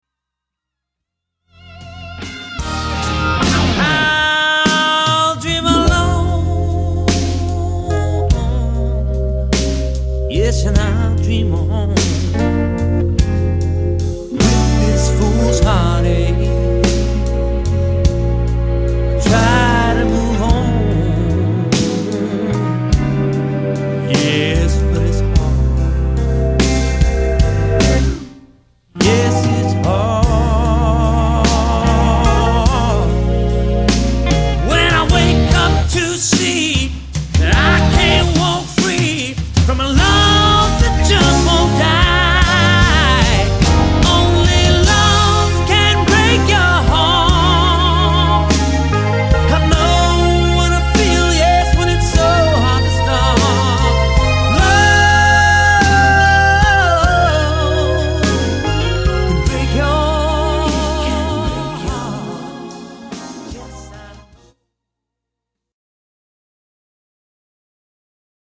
lead vocals and all guitars
drums on all tracks
bass on all tracks
keys, B-3 on all tracks
keys, piano and Wurlizer
all strings